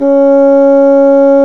Index of /90_sSampleCDs/Roland L-CDX-03 Disk 1/WND_Bassoons/WND_Bassoon 2
WND BASSOO0F.wav